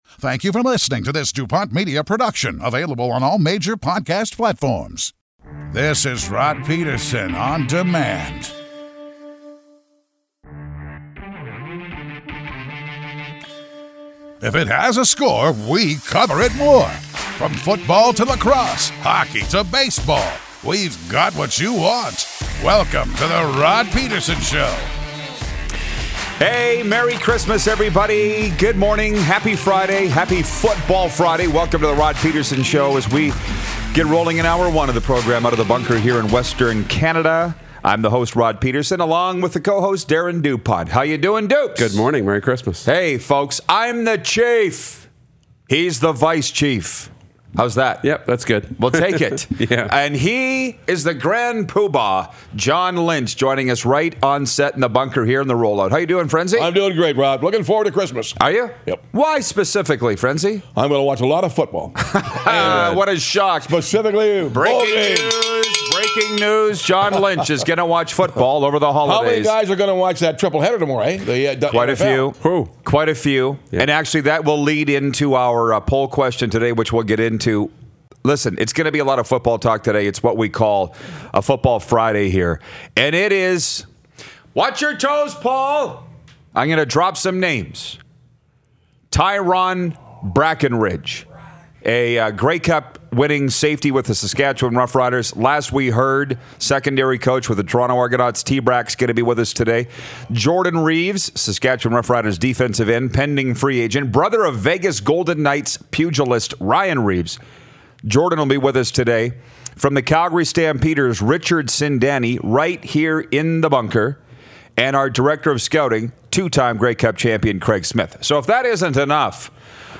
Come on, it’s lovely weather for some sports talk together with you! It’s a Frenzy Friday!